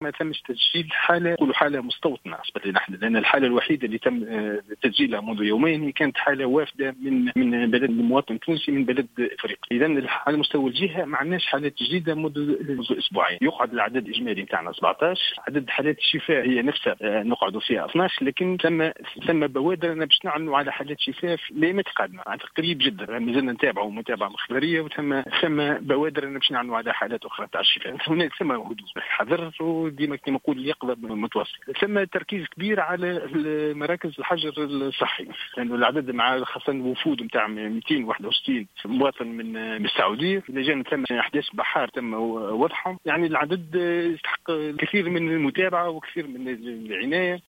تصريح للجوهرة أف-أم